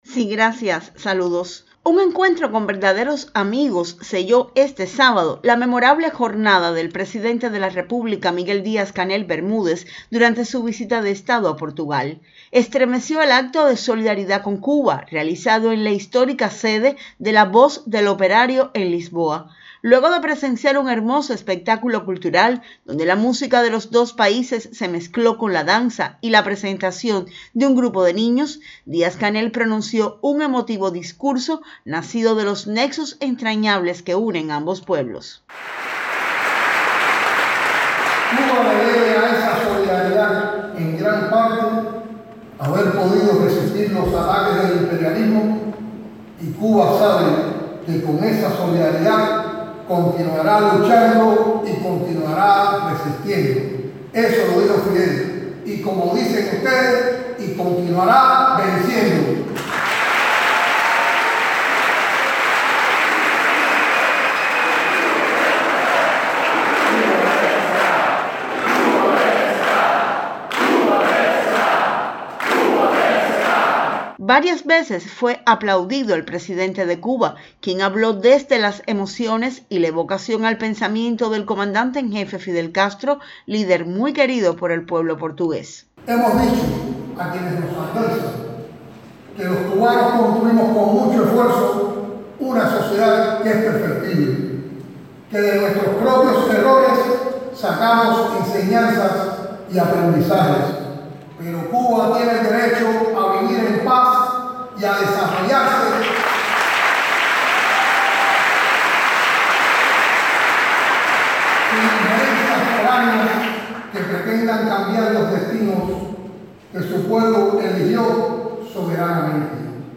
En el corazón de Lisboa cientos de voces amigas se unieron para gritar “Cuba sí, bloqueo no”, y para reafirmar que la Revolución cubana es fuente de inspiración. Fue un acto de solidaridad inolvidable que contó con la presencia del Primer Secretario del Comité Central del Partido comunista y Presidente de la República de Cuba, Miguel Díaz-Canel Bermúdez.